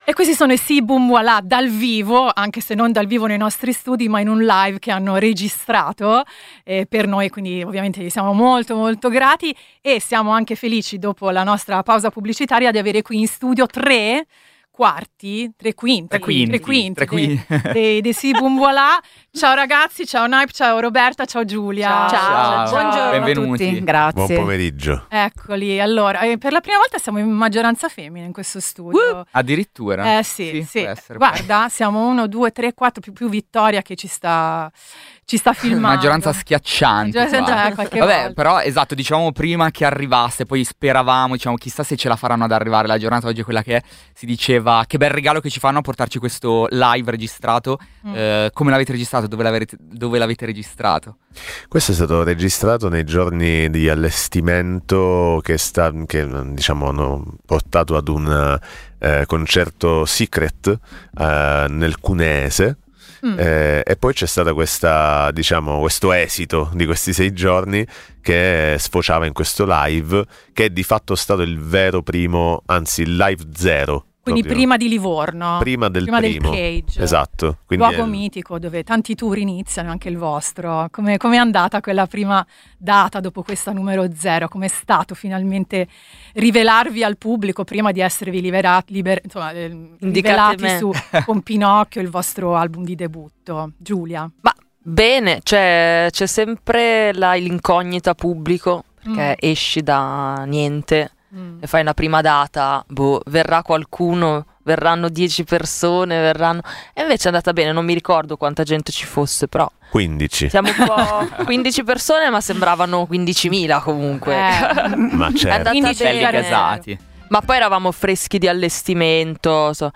Ascolta l’intervista ai Si! Boom! Voilà!